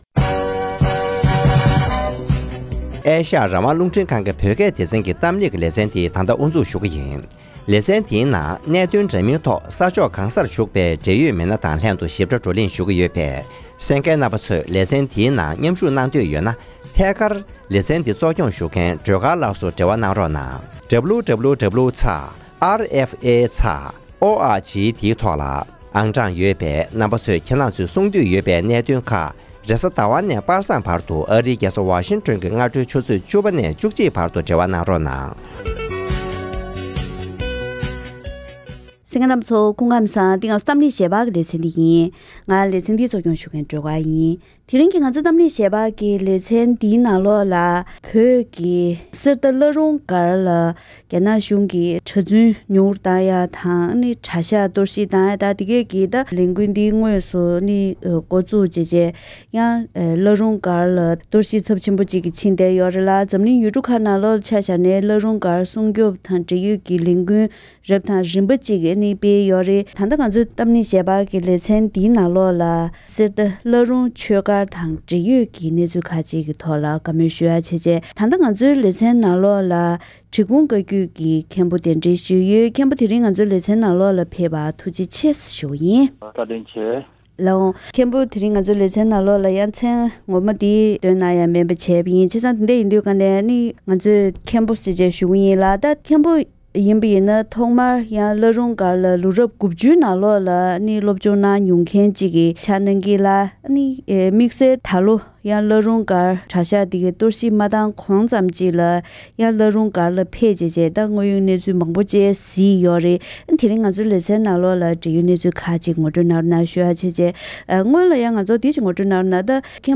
ཐེངས་འདིའི་གཏམ་གླེང་ཞལ་པར་ལེ་ཚན་ནང་།
བླ་རུང་སྒར་ལ་སློབ་སྦྱོང་དུ་སྐྱོད་མྱོང་མཁན་ལ་བཀའ་མོལ་ཞུས་པ